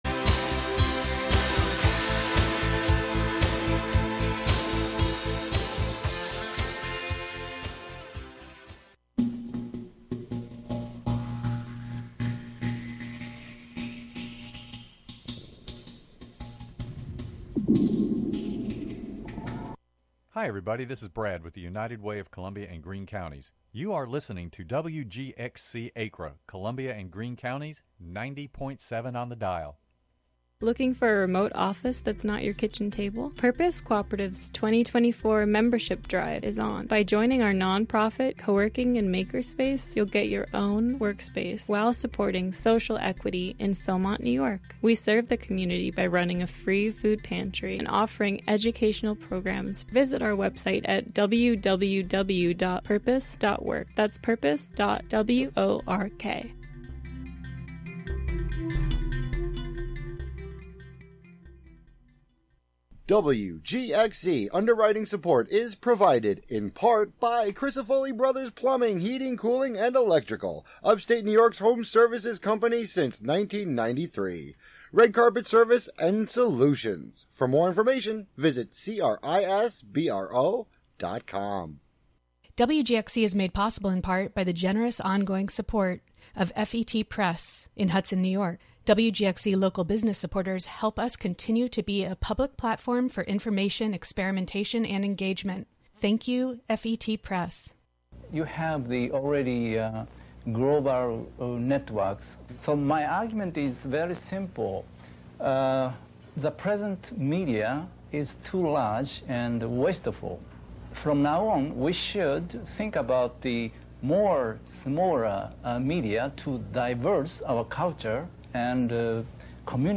jazz from the 30s
guitar
some nostalgic duos from the 60's